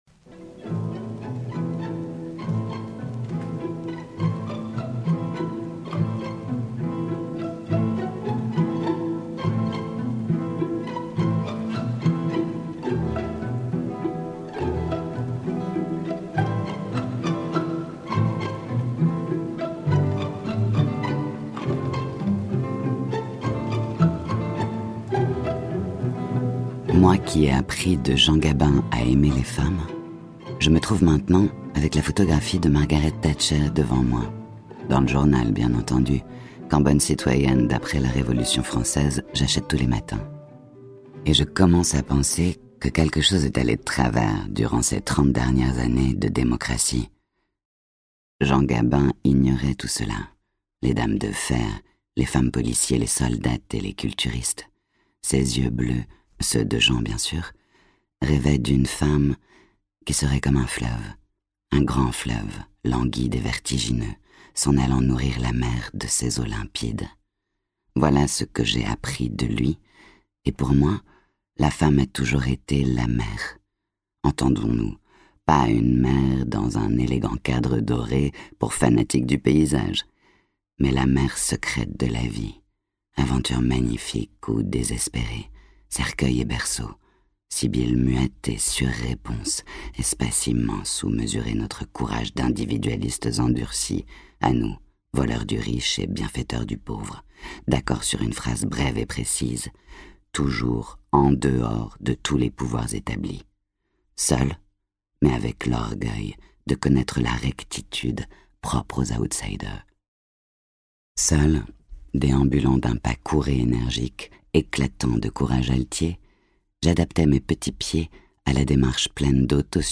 Écoutez un extrait du livre audio Moi, Jean Gabin de Goliarda Sapienza lu par Anna Mouglalis